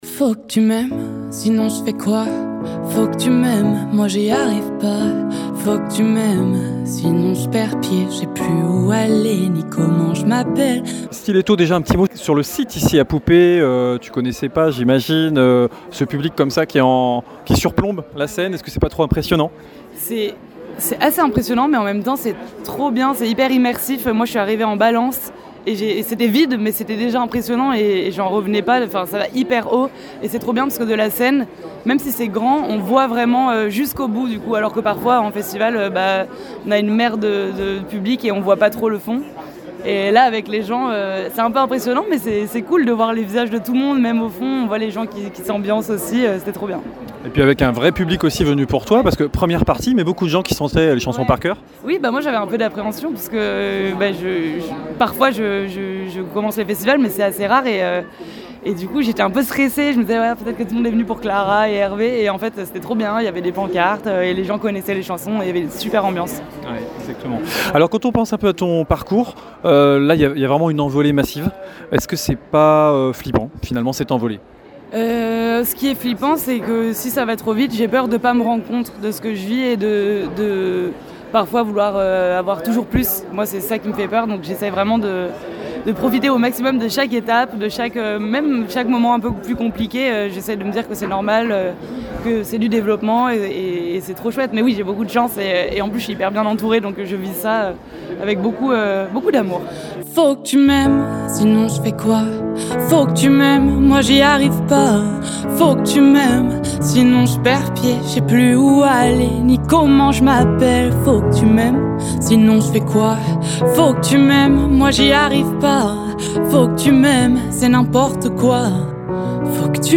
à l'occasion de son passage à Poupet
Interview